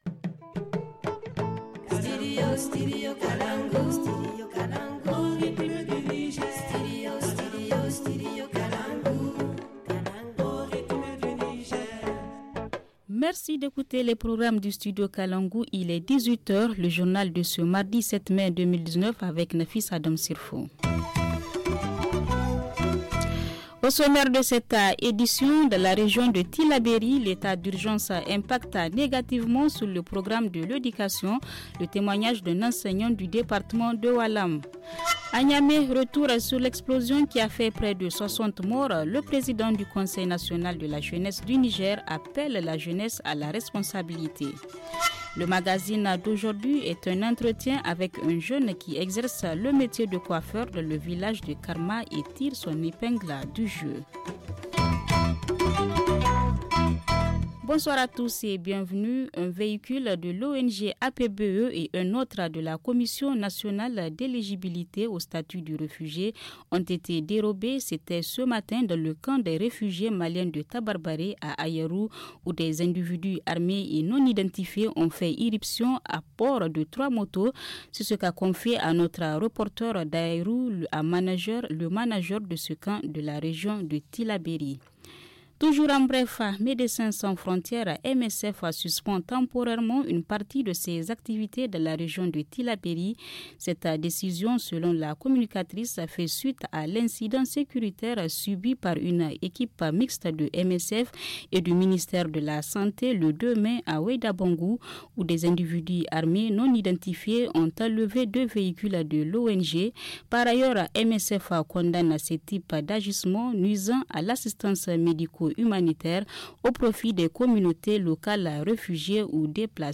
Le journal en français